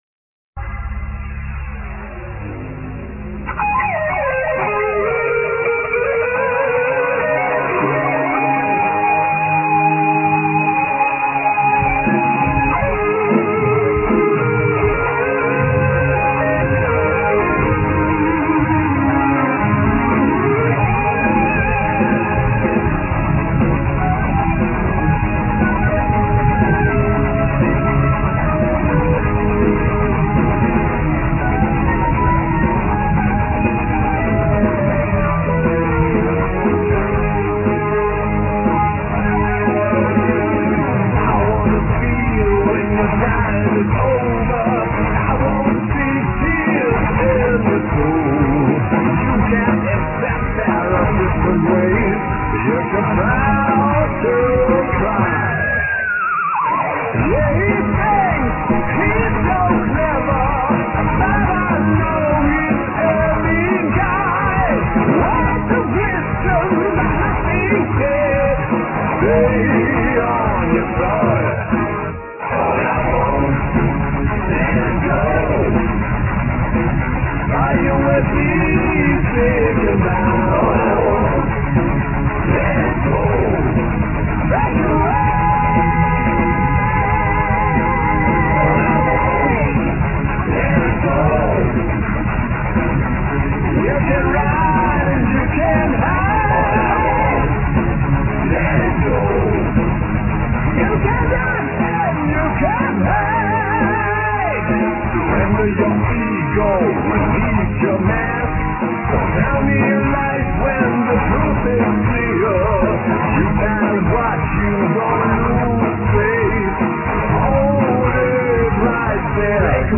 Vocals, Guitars and Sitars
Bass
Drums
Keyboards